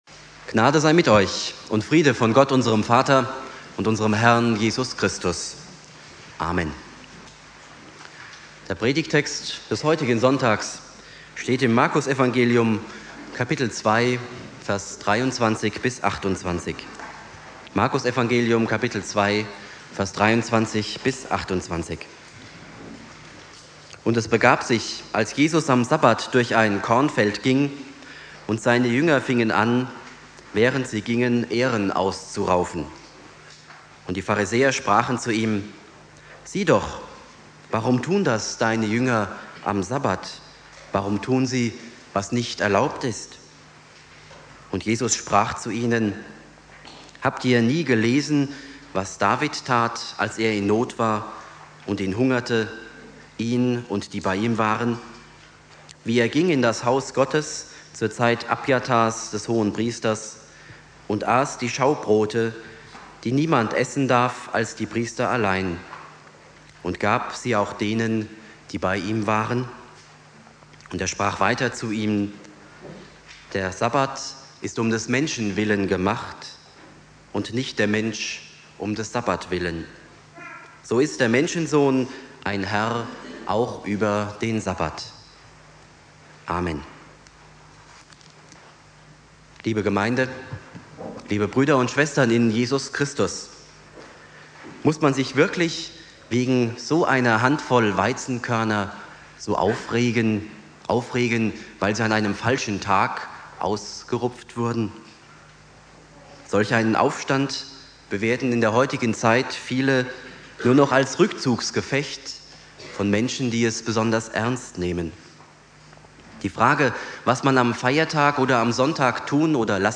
Predigt
Predigt im CVJM-Festgottesdienst